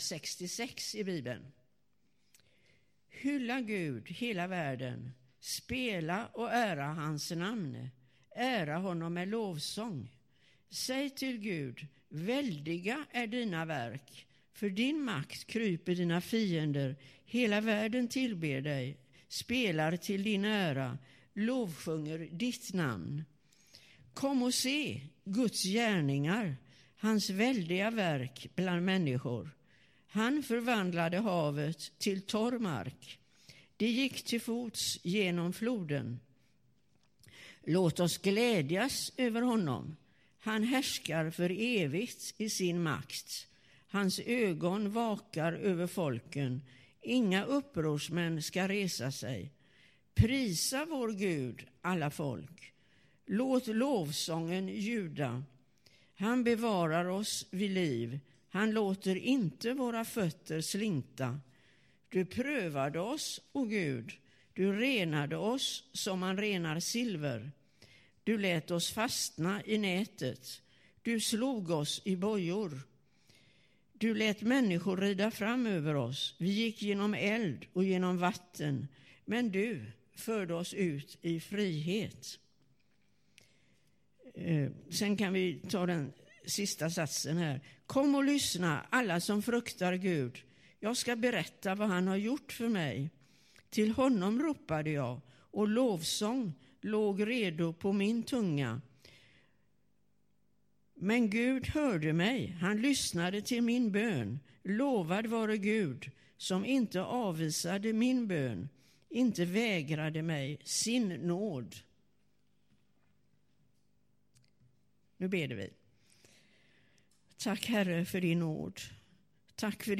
2017-10-29 Predikan av